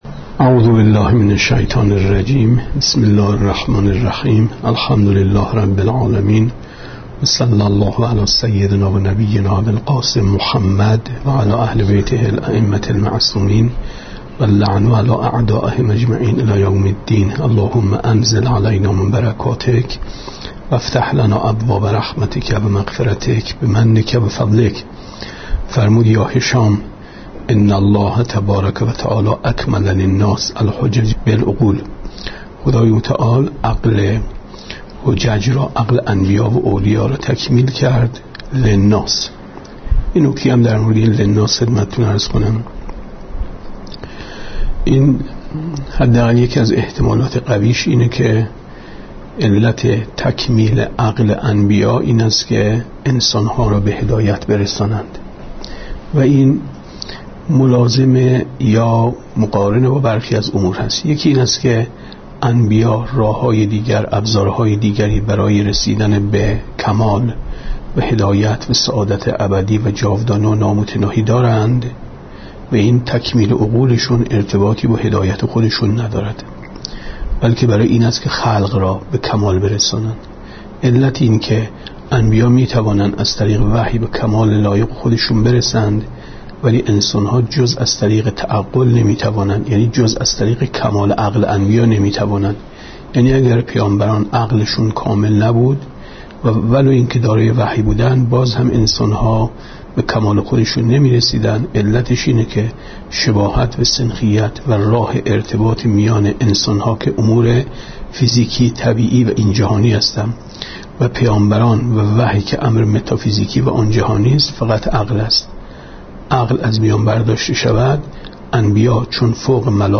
گفتارهای ماه مبارک رمضان 1436 ـ جلسه هشتم ـ 9/ 4/ 94 ـ شب چهاردهم ماه رمضان